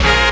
JAZZ STAB 29.wav